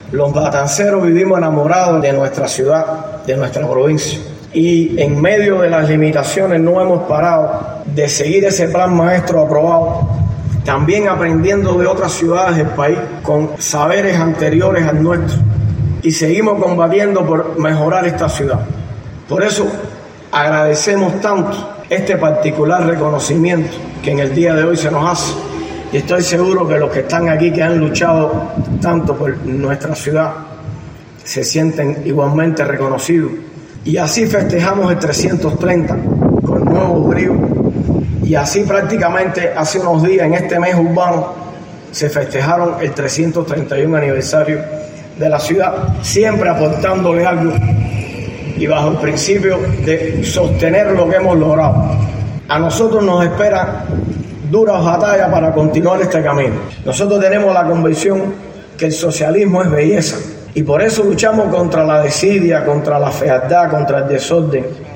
Palabras de Mario Sabines Lorenzo, primer secretario del Partido Comunista de Cuba, tras reconocimiento entregado a la ciudad de Matanzas.